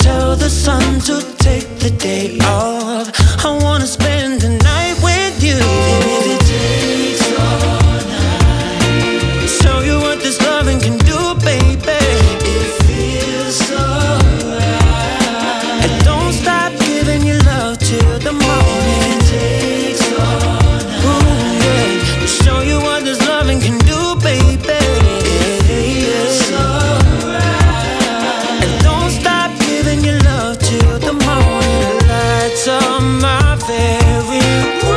Off-beat гитары и расслабленный ритм
Reggae
2025-02-07 Жанр: Регги Длительность